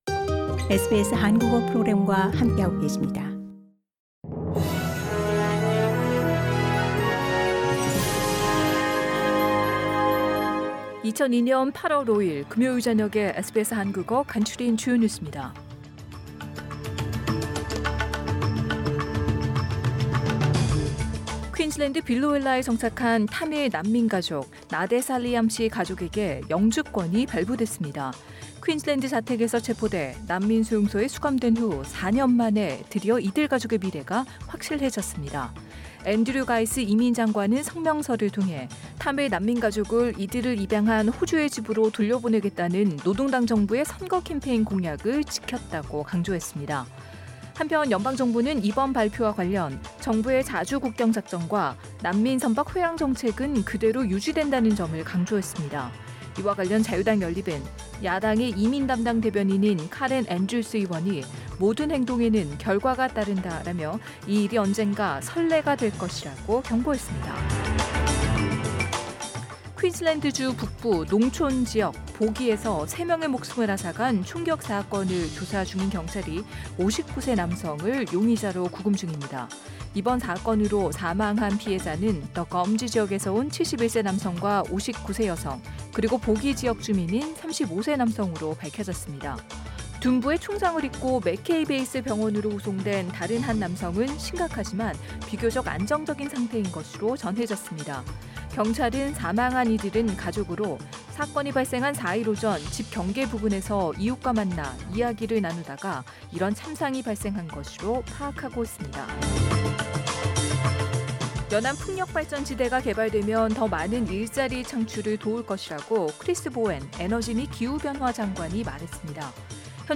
2022년 8월 5일 금요일 저녁 SBS 한국어 간추린 주요 뉴스입니다.